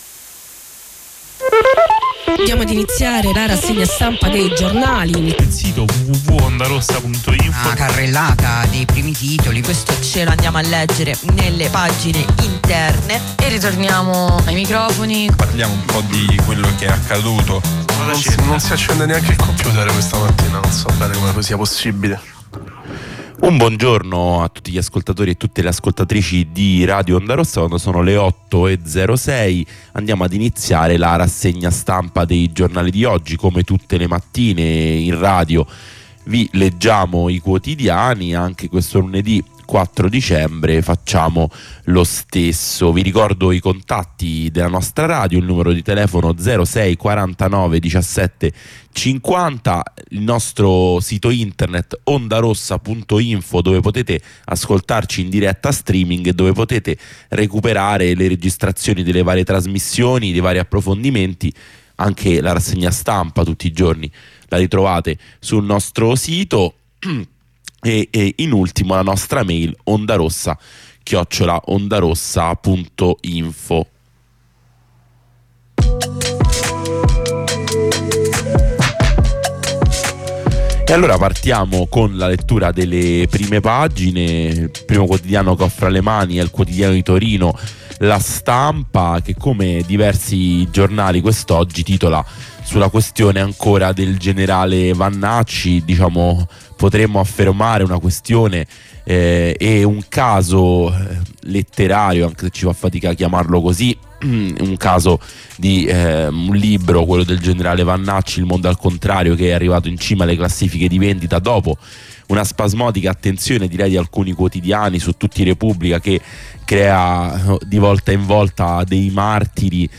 Lettura e commento dei quotidiani.